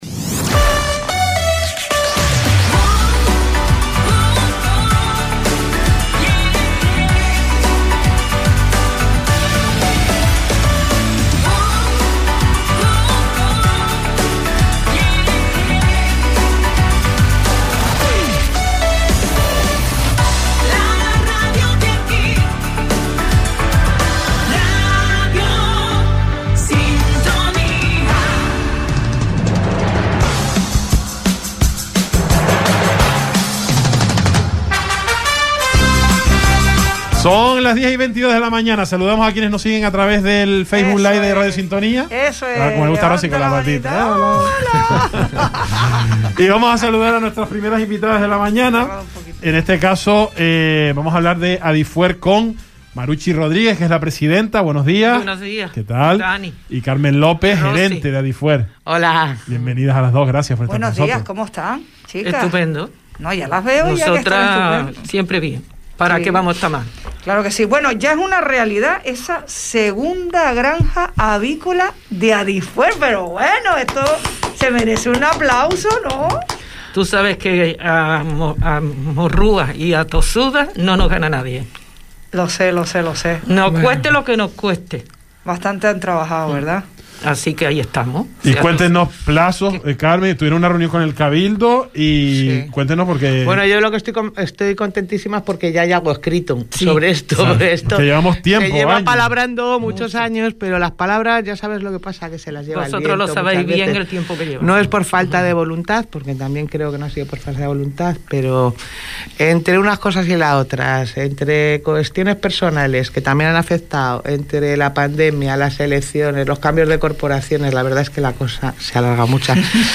El Salpicón, entrevista